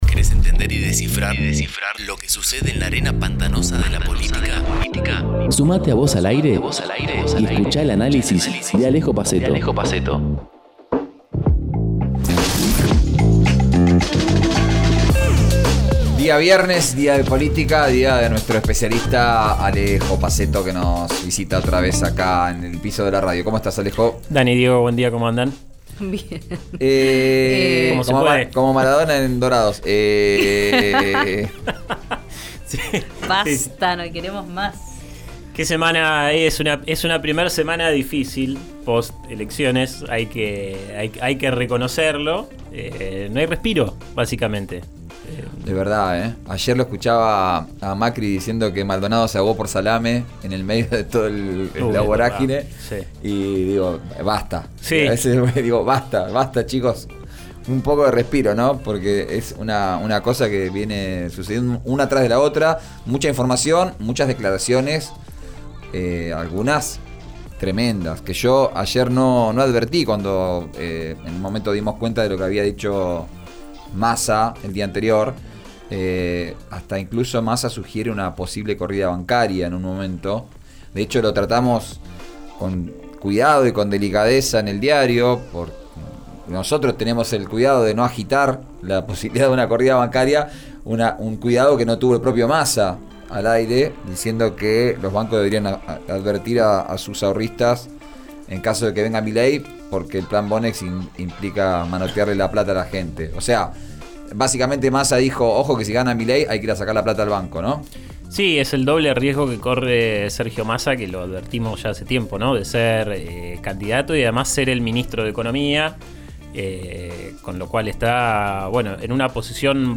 Nueva columna en RÍO NEGRO RADIO